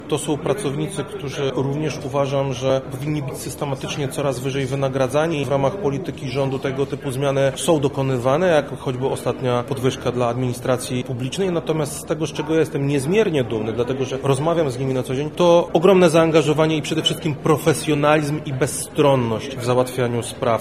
Dzisiaj (18.02) w Sali Błękitnej Lubelskiego Urzędu Wojewódzkiego zorganizowano obchody Dnia Służby Cywilnej.
Komorski służba cywilna – mówi Krzysztof Komorski, wojewoda lubelski